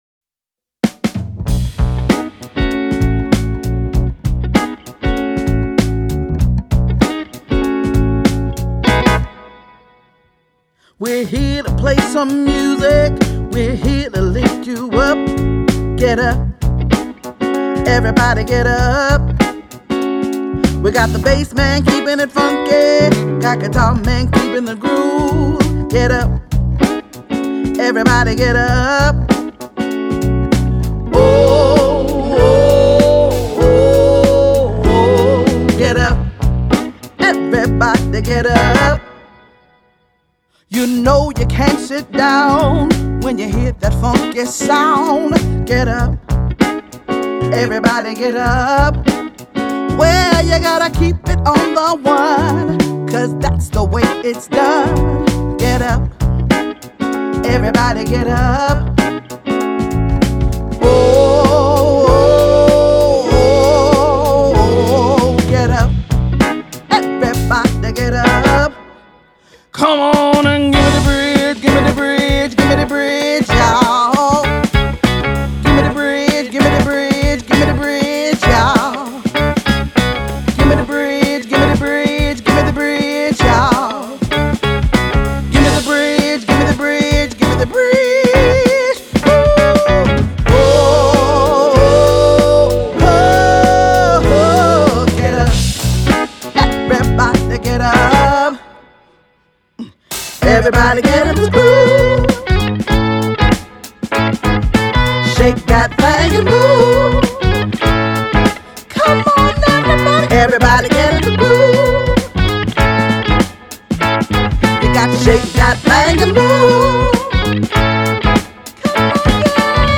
feel good music jam